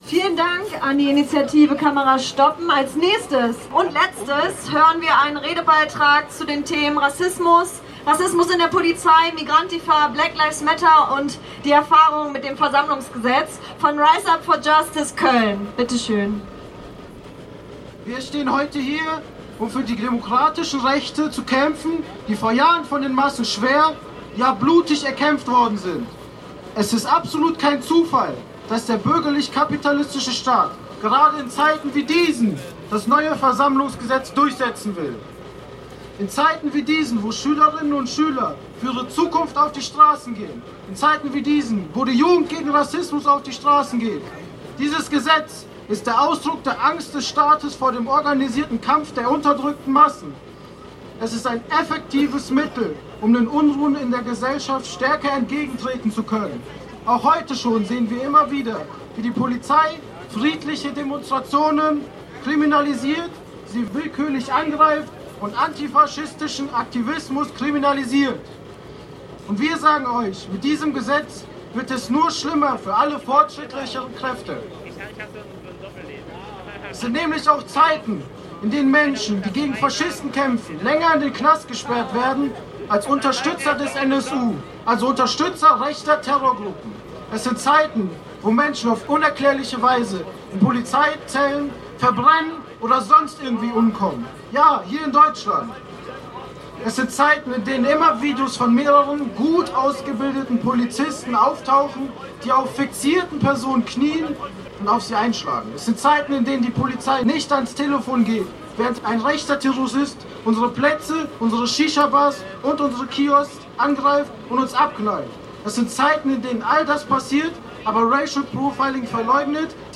Abschlusskundgebung
Am Zielpunkt angekommen, lauschten die Demonstrierenden den Abschlussreden.
Redebeitrag von „Riseup for Justice Köln“
Die Beiden RednerInnen sprachen zu folgenden Themen: